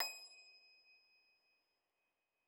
53l-pno23-D5.wav